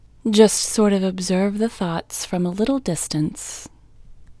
LOCATE OUT English Female 6